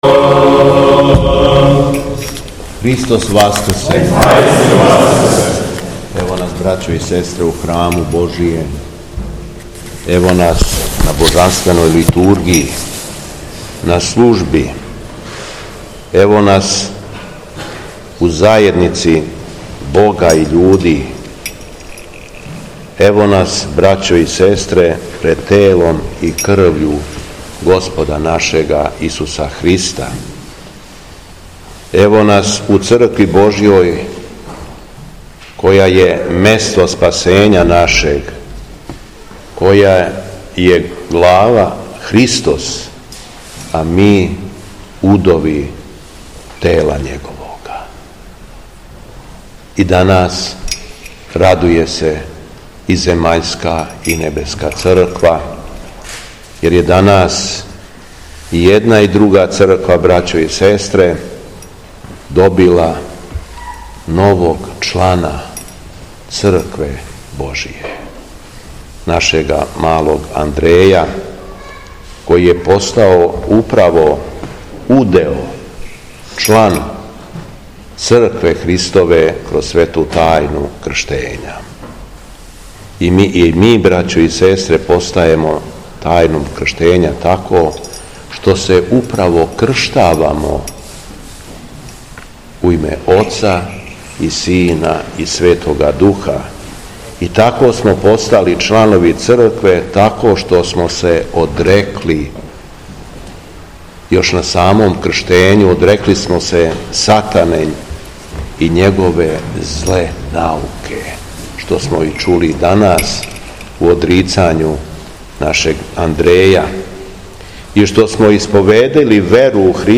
СВЕТА АРХИЈЕРЕЈСКА ЛИТУРГИЈА У СТАРОЈ ЦРКВИ У КРАГУЈЕВЦУ - Епархија Шумадијска
Беседа Његовог Високопреосвештенства Митрополита шумадијског г. Јована